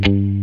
007LICKMUTEC.wav